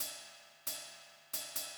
KIN Beat - Rides.wav